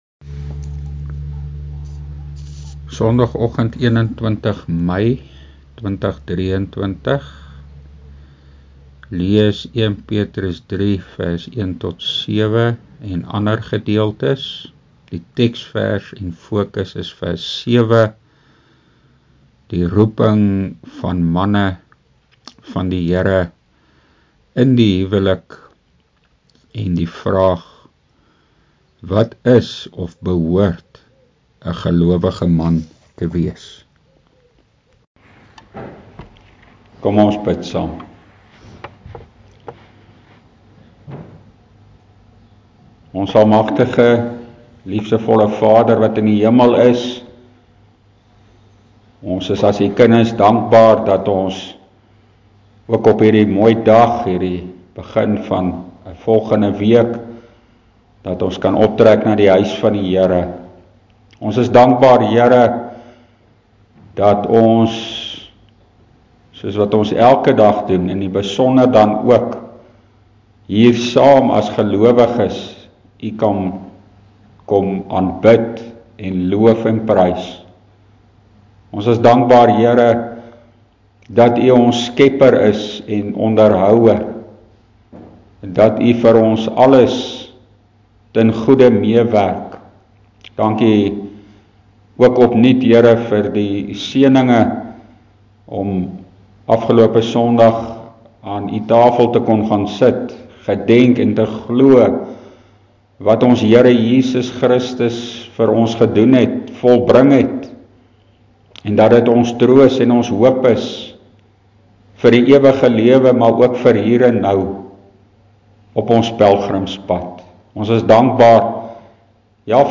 Opname (GK Carletonville, 2023-05-21):